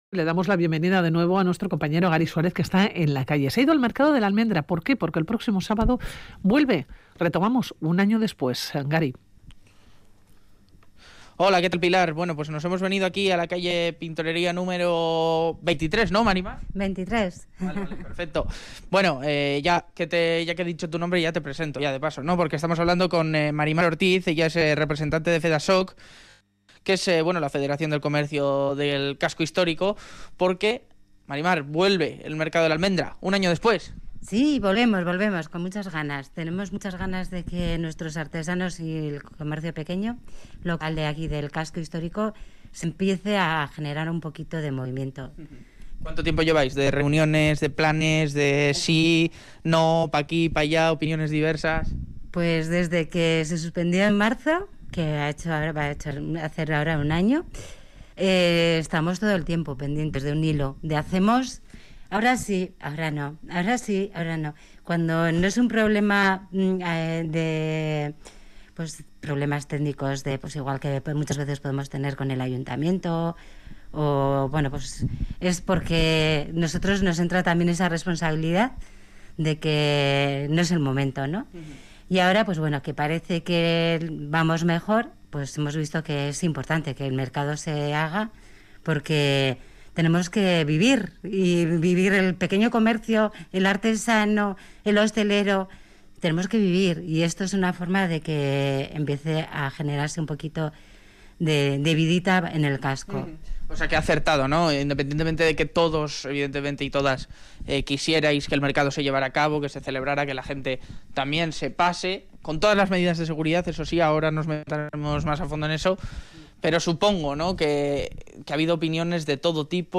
En Radio Vitoria nos desplazamos a la calle Pintorería